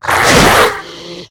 monstermiss.ogg